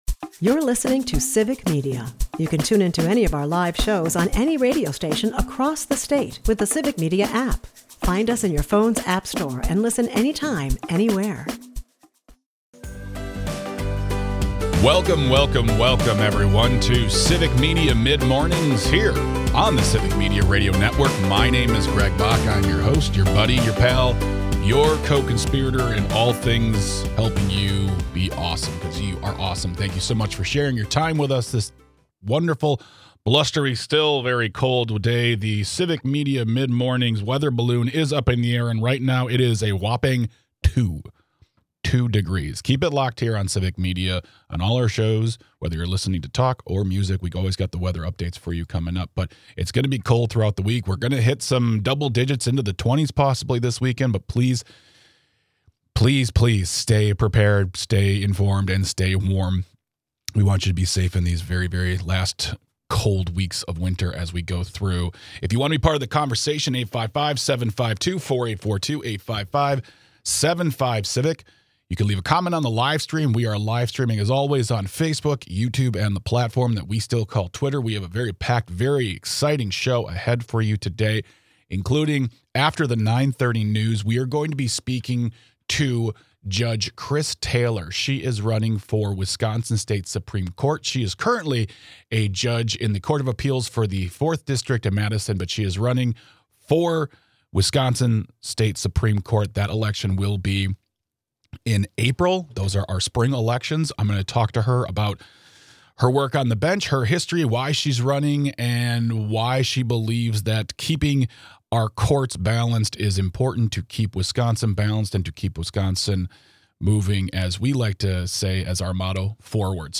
Next, Judge Chris Taylor serves in the District IV Court of Appeals and is currently a candidate for State Supreme Court . She joins us to talk about her campaign, why she is running and what sets her apart from her opponent .